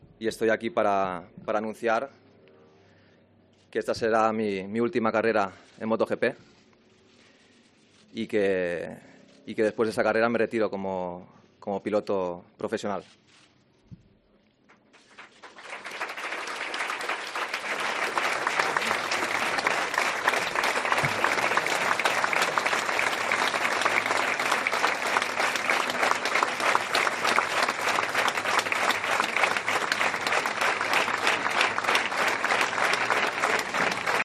AUDIO: Jorge Lorenzo anuncia la seva retirada en roda de premsa al Circuit de Cheste